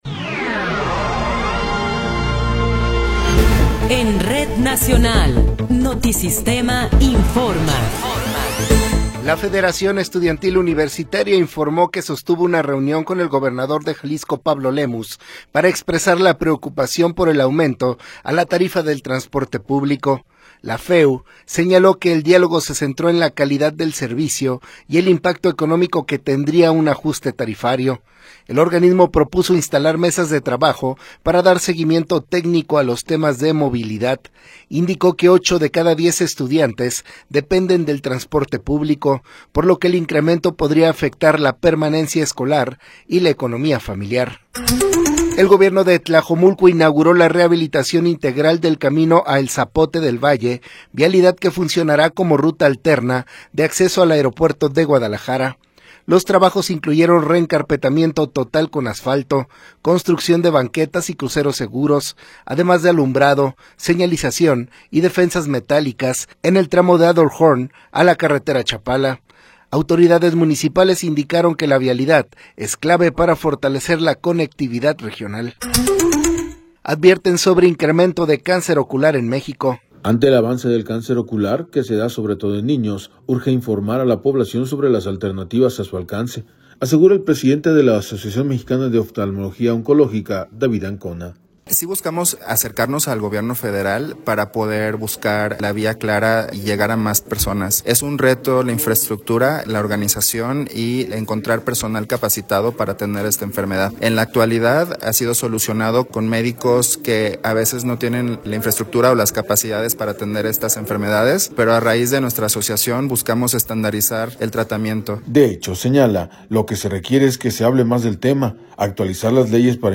Noticiero 18 hrs. – 9 de Enero de 2026
Resumen informativo Notisistema, la mejor y más completa información cada hora en la hora.